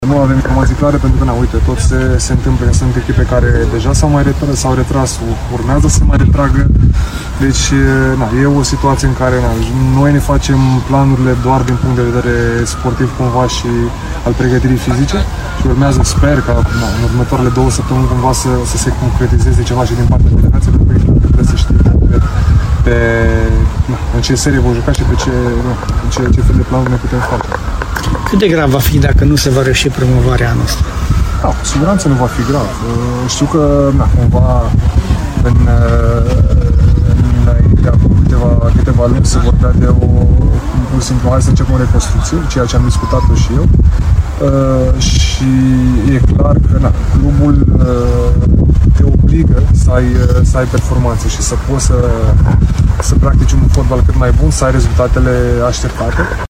Directorul executiv al clubului, Costel Pantilimon, a vorbit despre tinerețea lotului:
Pantilimon-despre-serie-si-eventuala-ratare-a-promovarii.mp3